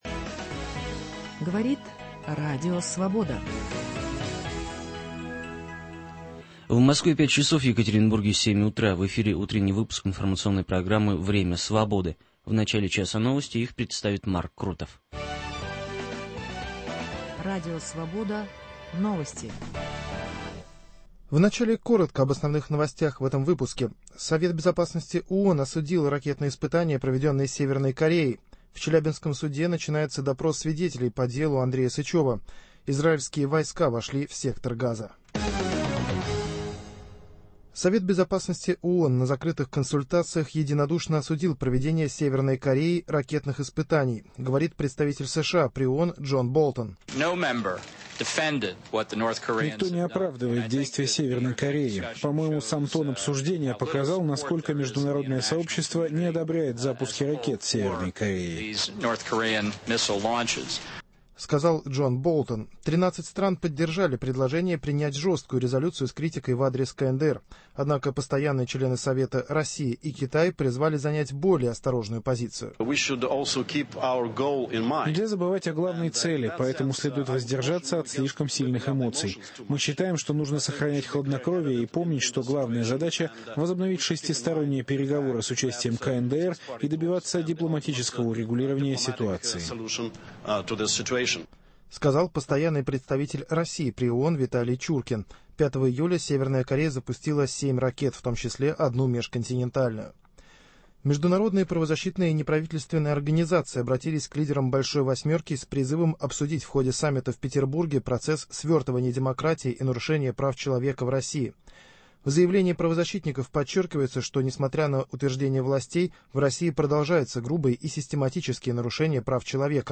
В Екатеринбургской студии Радио Свобода - председатель избирательной комиссии Свердловской области Владимир Мостовщиков.